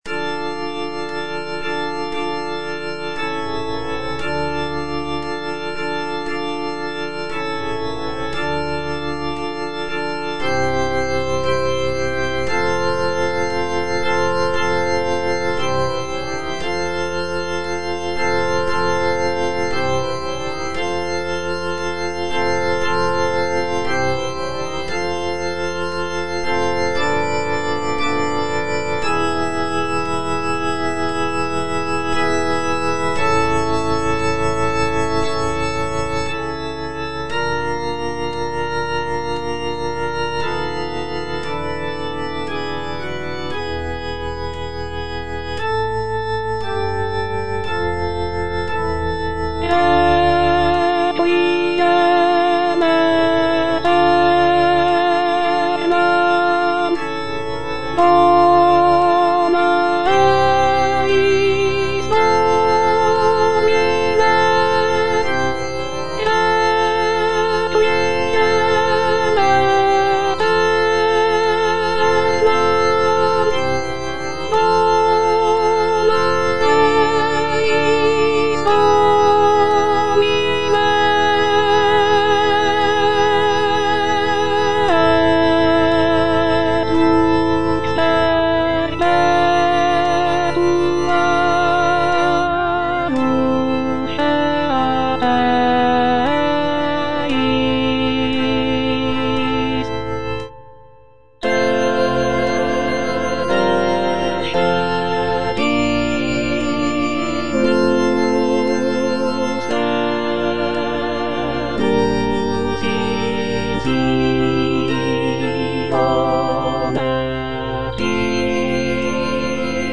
F. VON SUPPÈ - MISSA PRO DEFUNCTIS/REQUIEM Introitus (alto I) (Voice with metronome) Ads stop: auto-stop